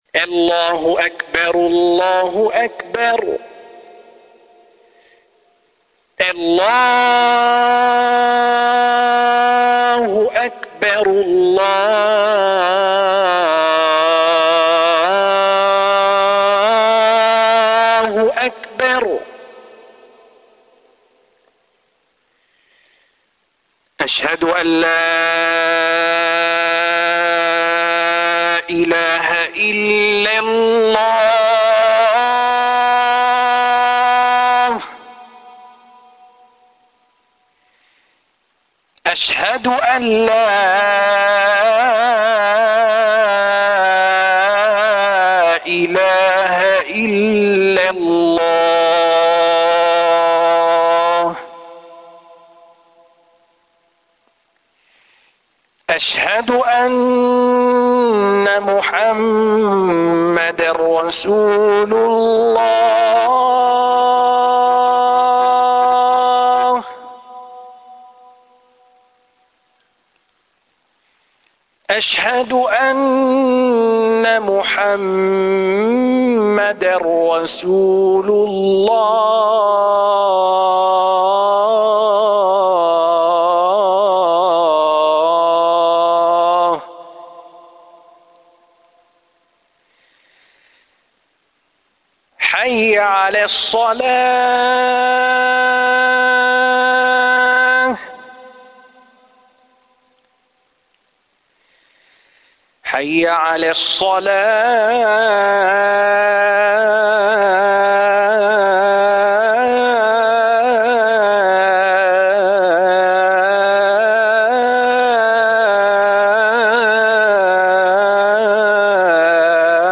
أناشيد ونغمات
أذان الفجر